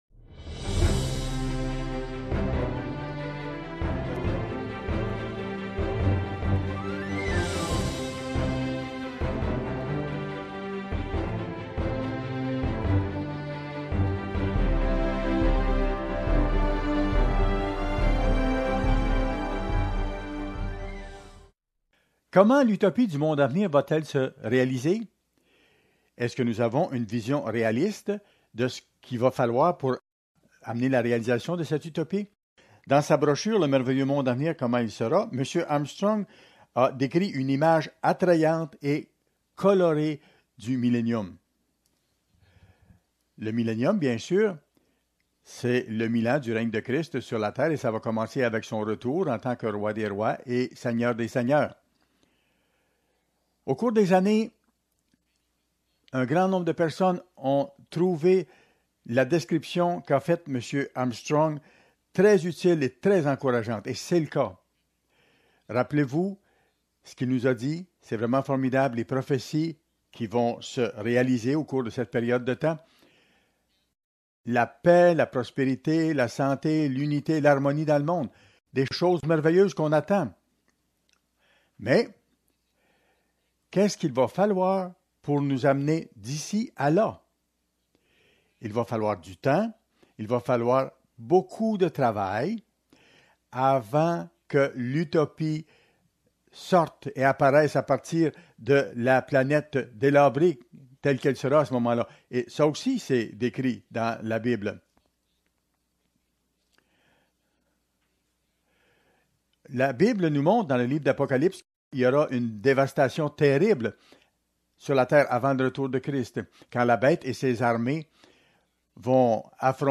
Fête des Tabernacles Comment l’utopie se réalisera-t-elle ?